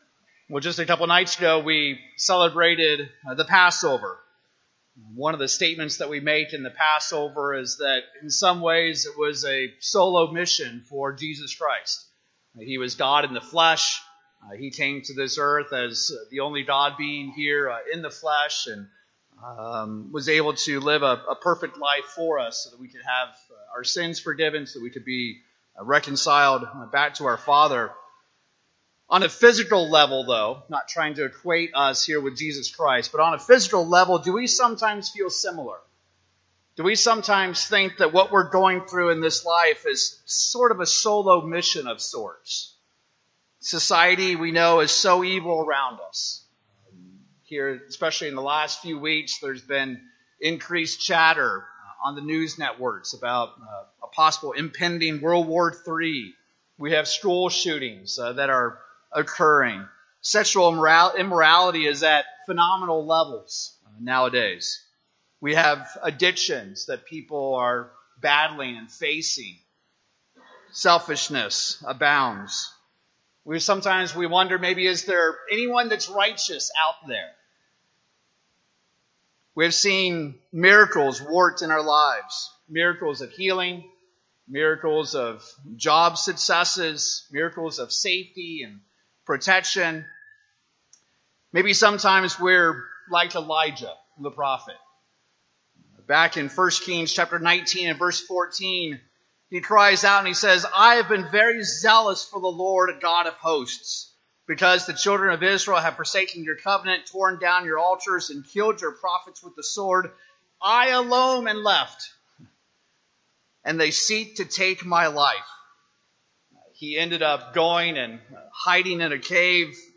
Holy Day Services Studying the bible?